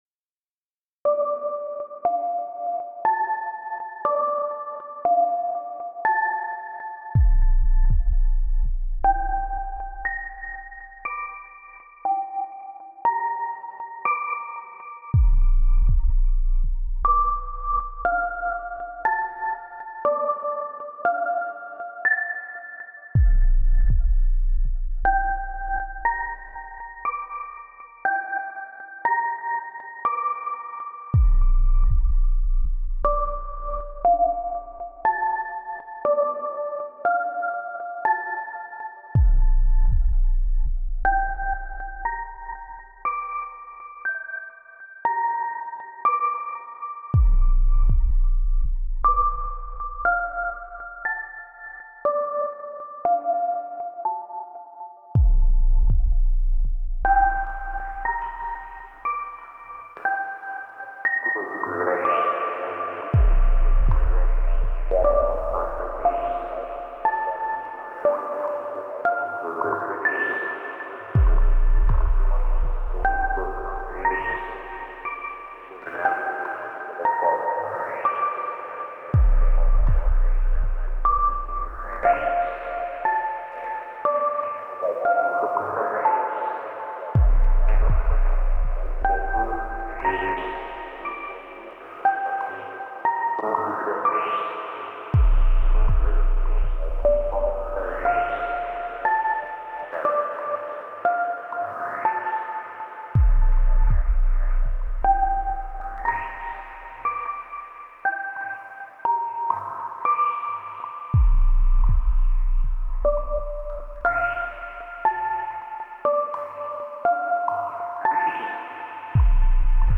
A mix of two recent releases